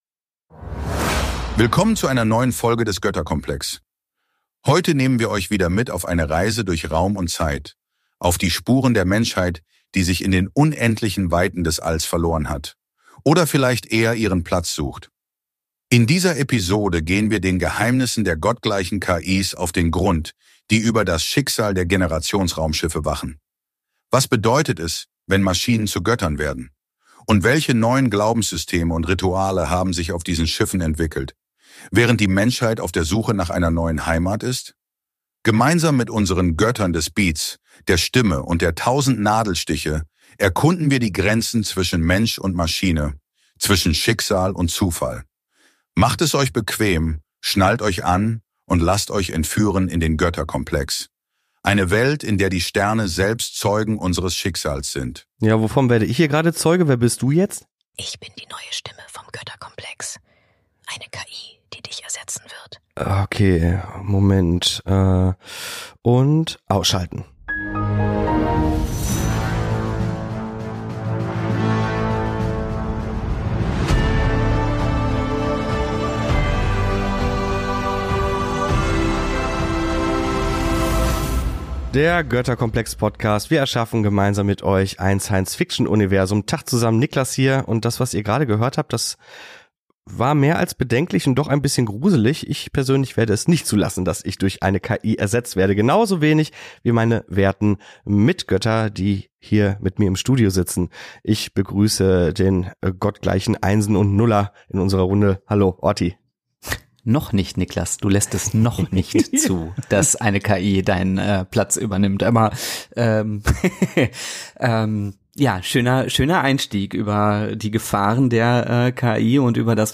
Und das ganze ohne Skript.